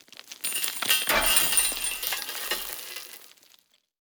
expl_debris_mtl_03.ogg